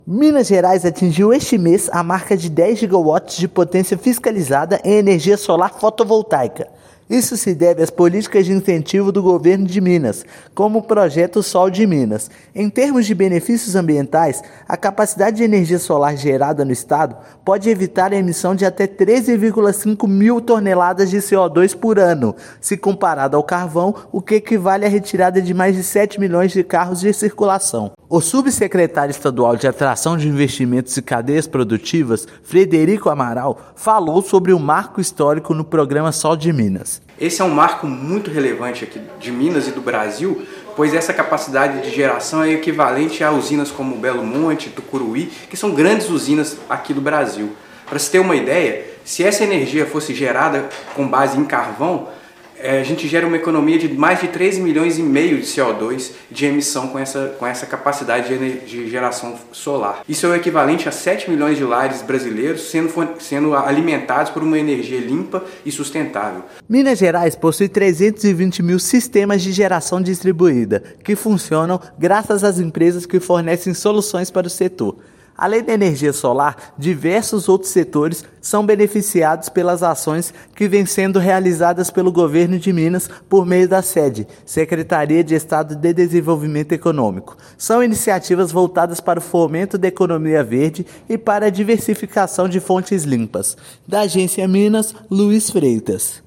Os 10 GW de potência fiscalizada em energia solar fotovoltaica gerados hoje no estado poderiam substituir a produção das usinas Petrobras, Eneva e UTE GNA I. Ouça matéria de rádio.